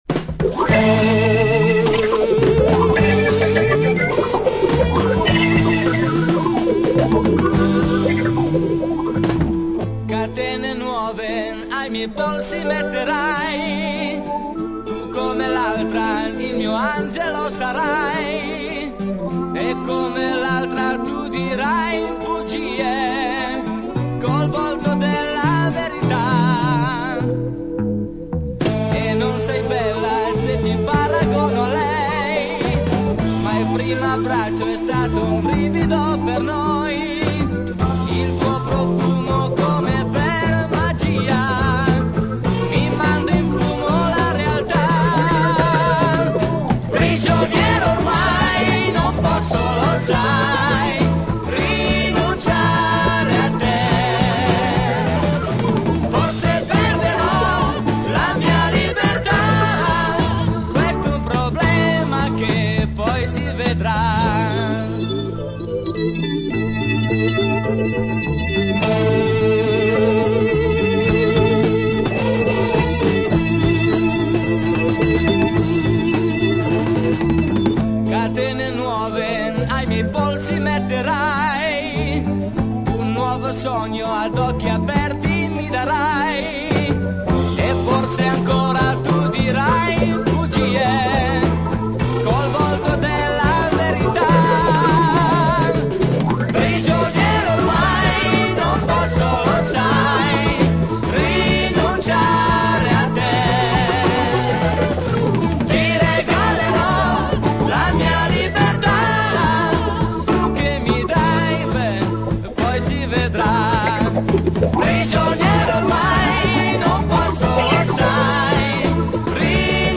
(Provini realizzati in sala prove)